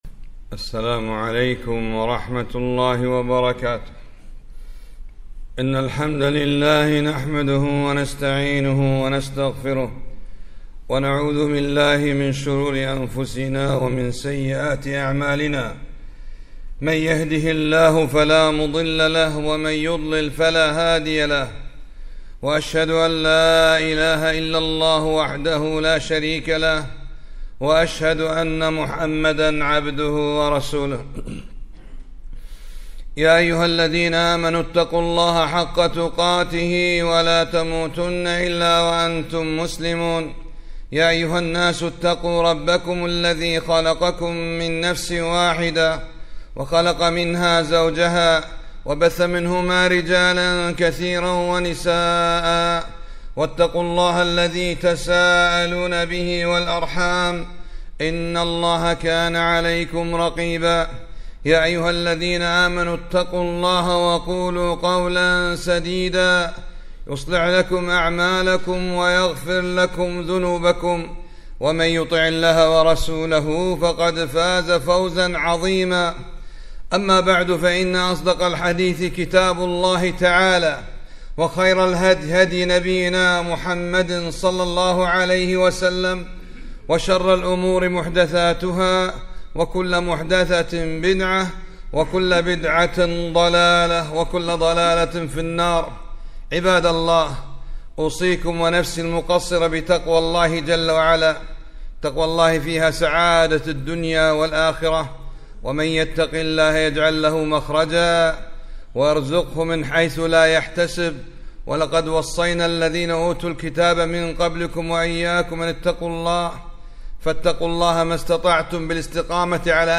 خطبة - قال النبي ﷺ ( الصدق برهان...)